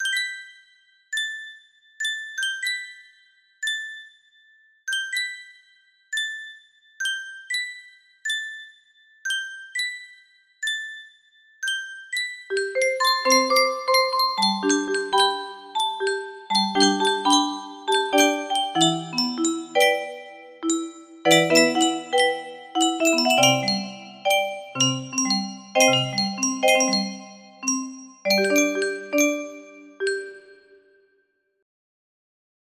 Unknown Artist - Untitled music box melody
Full range 60